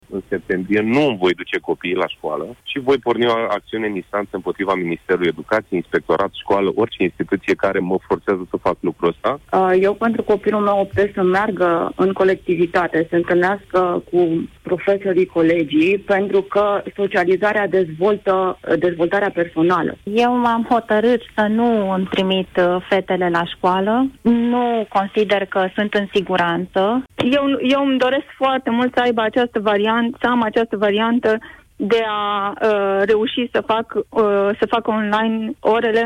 Majoritatea părinţilor care au intrat în direct alături de Cătălin Striblea spune că îşi vor ţine copiii acasă: